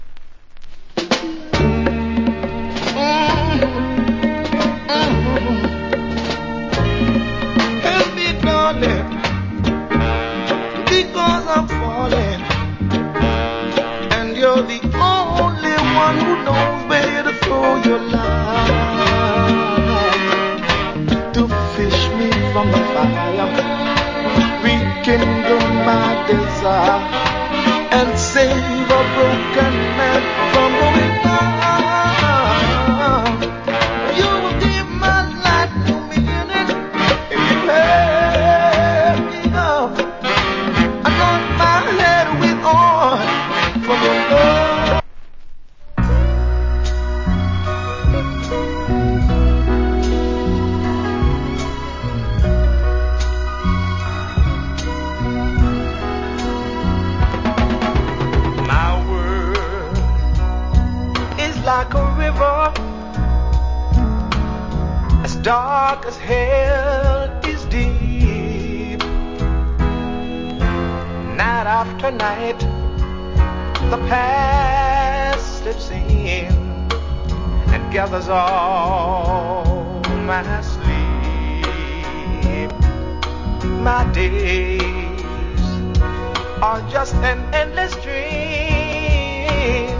Nice Duet Reggae Vocal.